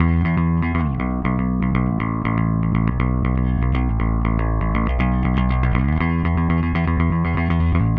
Power Pop Punk Bass 01b.wav